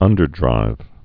(ŭndər-drīv)